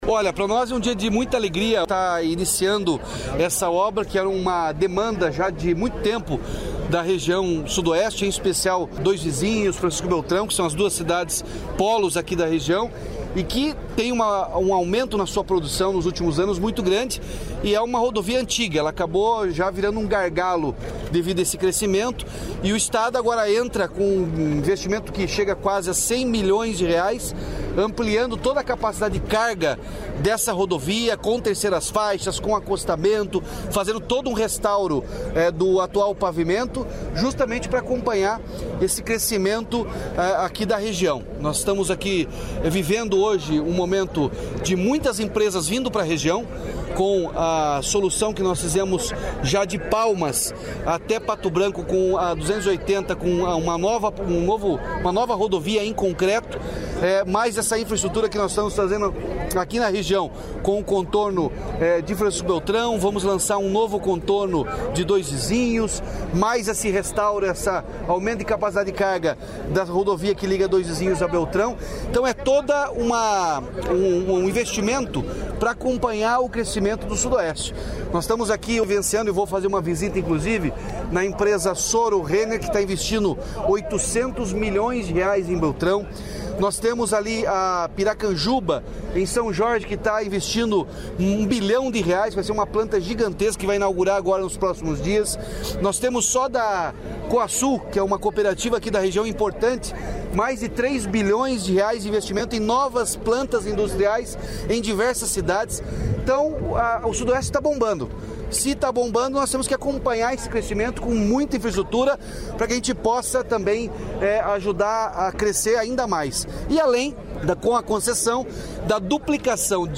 Sonora do governador Ratinho Junior sobre pedra fundamental da modernização de duas rodovias no Sudoeste